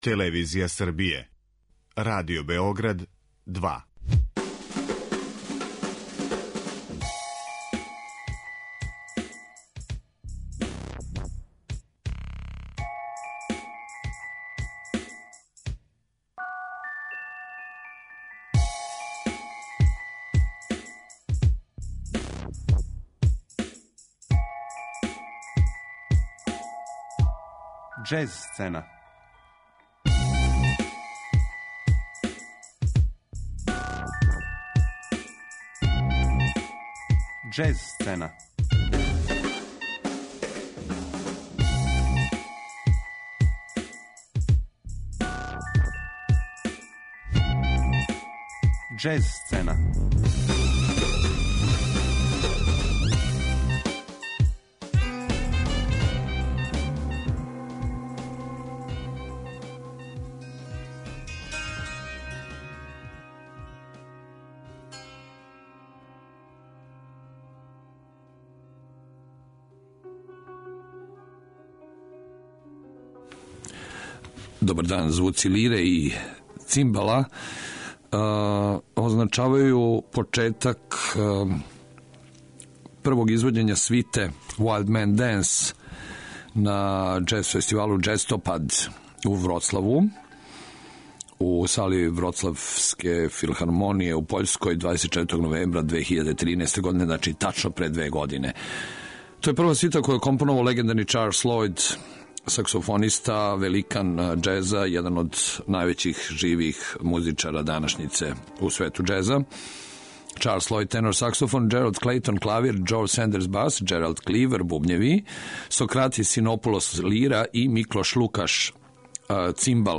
прву џез свиту чувеног саксофонисте
надахнут бразилском музиком